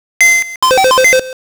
computeropen.WAV